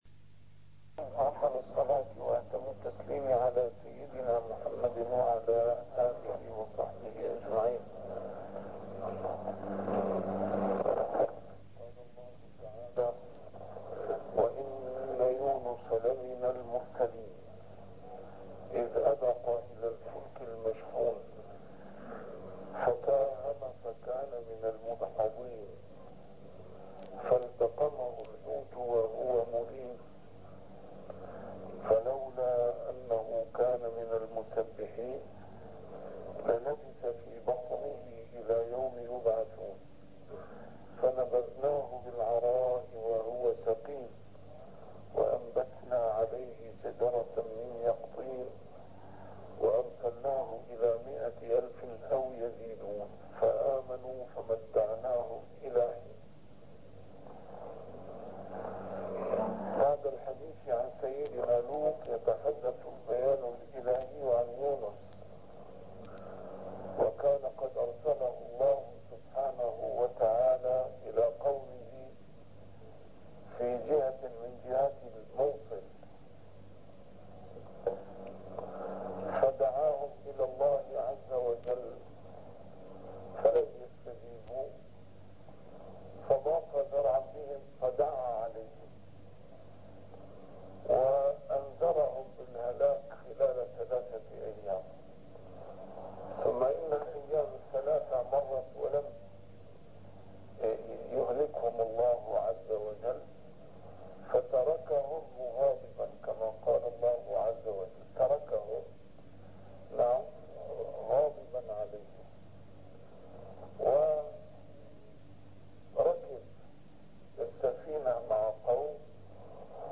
A MARTYR SCHOLAR: IMAM MUHAMMAD SAEED RAMADAN AL-BOUTI - الدروس العلمية - تفسير القرآن الكريم - تسجيل قديم - الدرس 458: الصافات 139-148